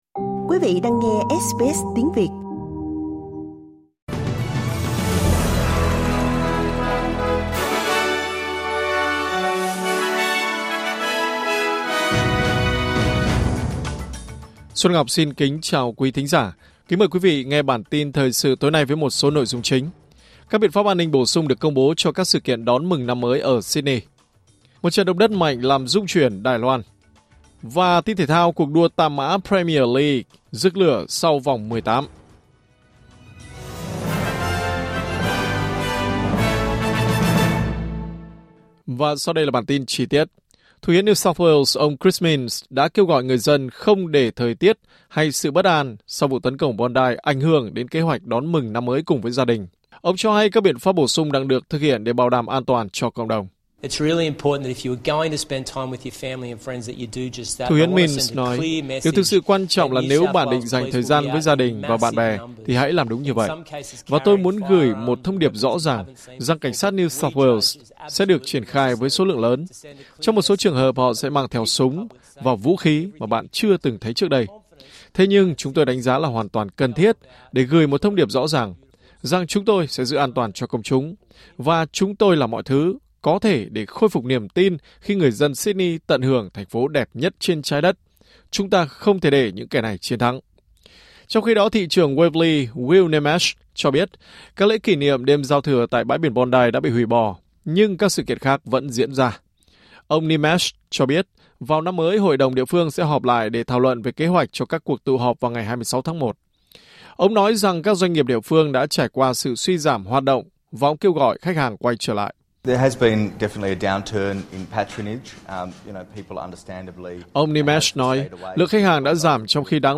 Bản tin của SBS Tiếng Việt sẽ có những nội dung chính.